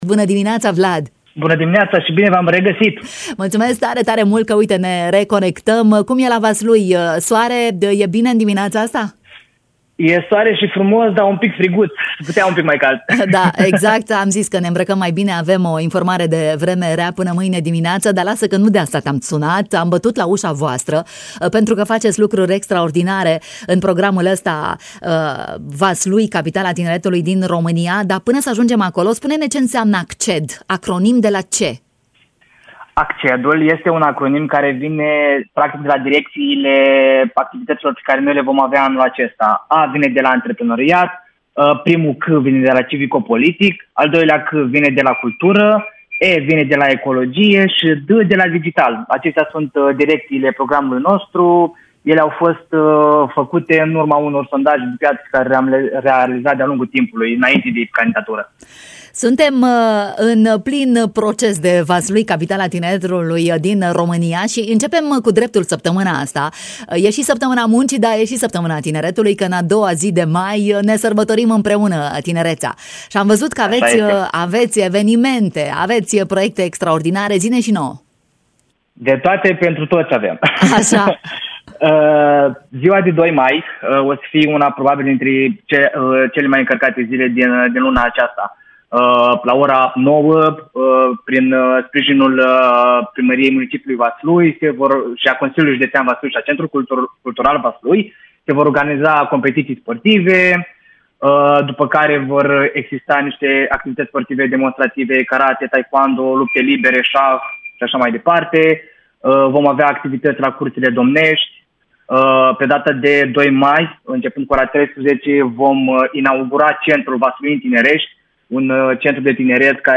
Capitala Tineretului din România ne-a adus toate detaliile în matinal.